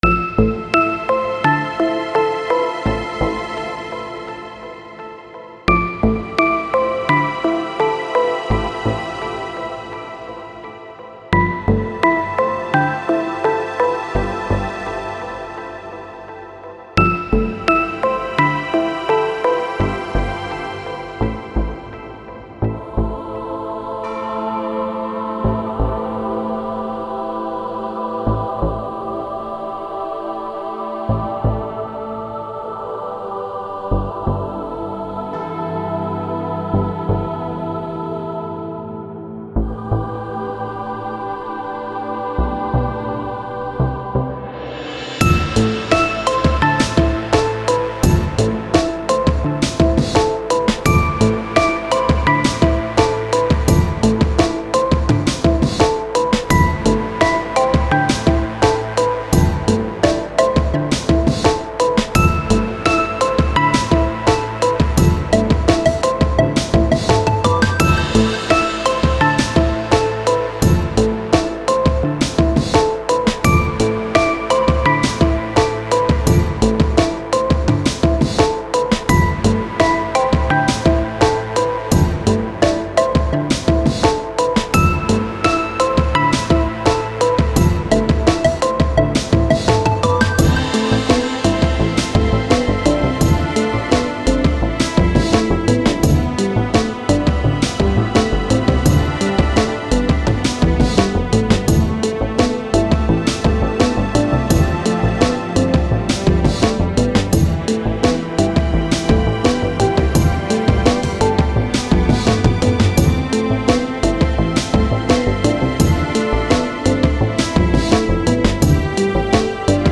This is a blend of ambience, electronic, and DnB styles of music (with orchestral elements) that give the song a sort of dark, pensive f ...
posted 4 weeks ago Musician This is a blend of ambience, electronic, and DnB styles of music (with orchestral elements) that give the song a sort of dark, pensive feeling. It's reminiscent of music from the older Kingdom Hearts games.